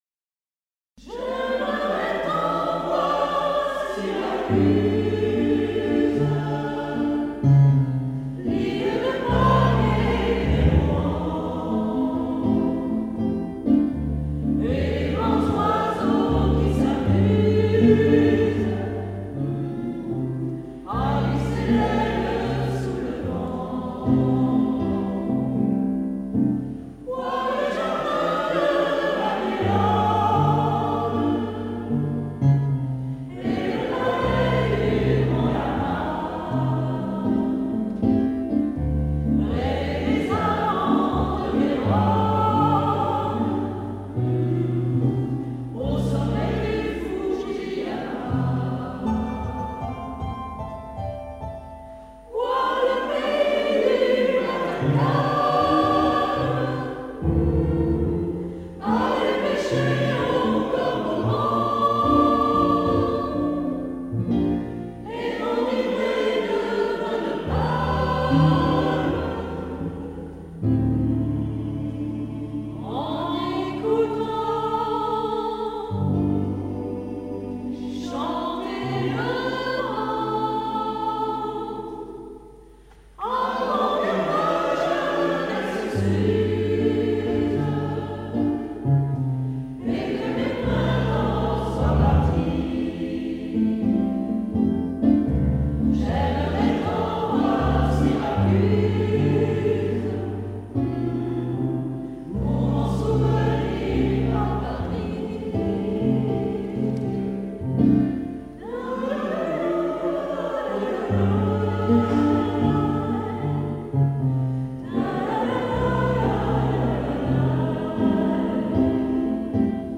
Arrangement vocal